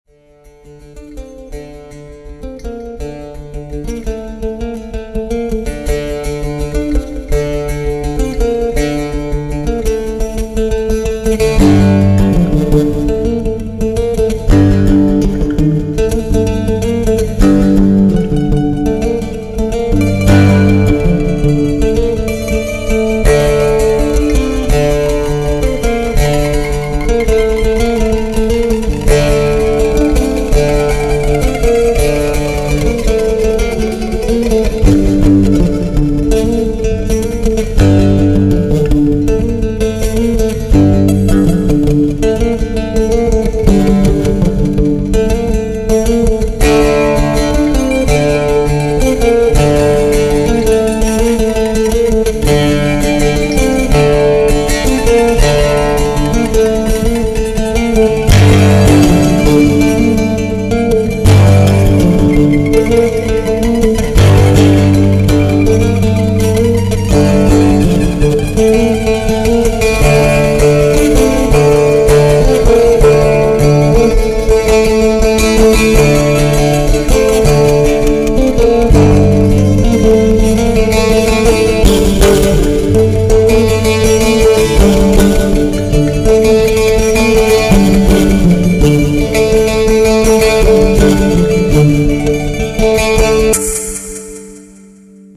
( tendances cordes) 2 morceaux rapides
Le song fait un peu brouillon, ya trop d'écho mais c'est le style qui veu ça.
Les graves saturent.
L'intrument que tu utilises a un bon song acoustique
Merci :bravo: ( effectivement, la prise de son pour le premier morceau c'était pas terrible)
L'effet glin glin, vient de mon instrument qui est en fait une mandole.
Donc, en fait je joue les accords en même temps que la mélodie ( c'est chaud....)